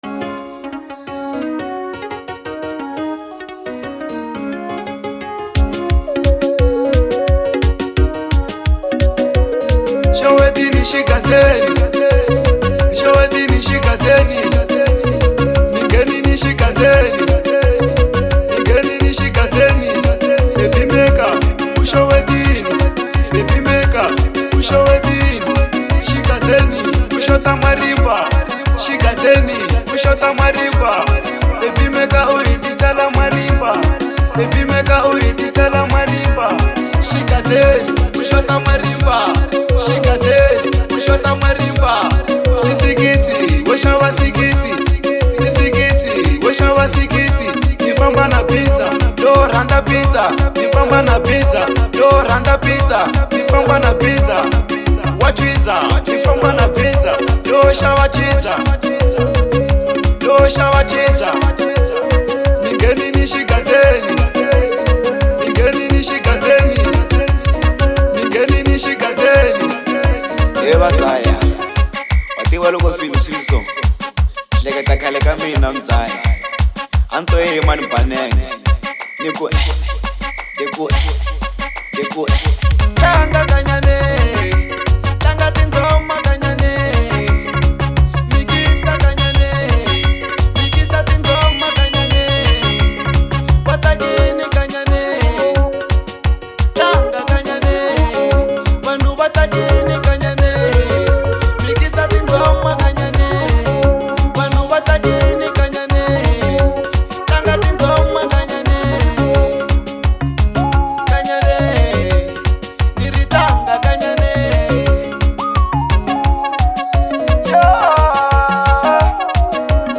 04:46 Genre : Xitsonga Size